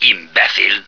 flak_m/sounds/male1/est/M1sucker.ogg at 9e43bf8b8b72e4d1bdb10b178f911b1f5fce2398